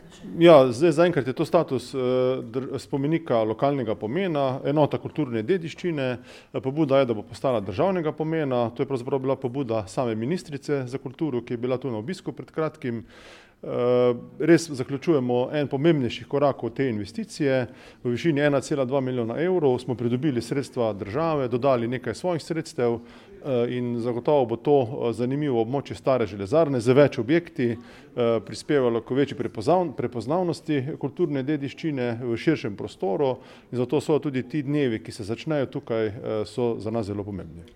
izjava Rozen 2.mp3